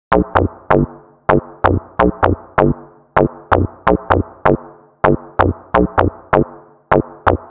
Lis Bass Loop.wav